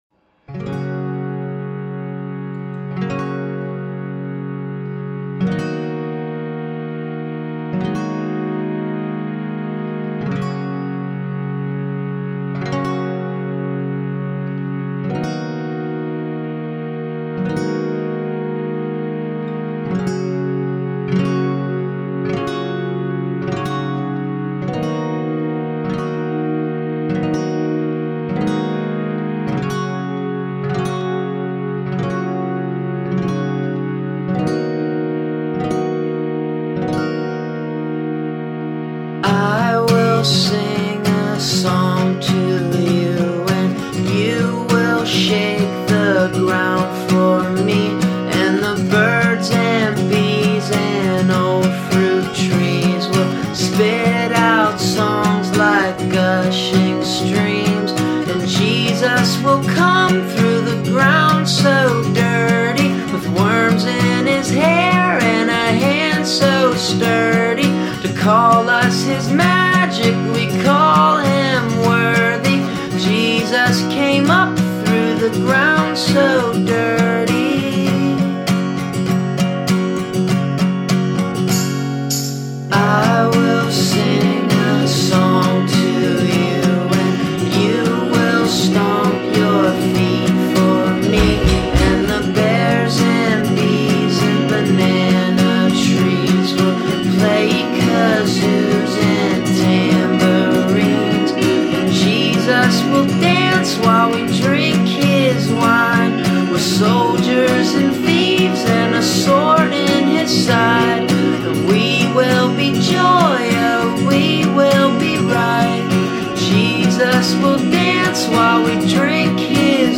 lilty drone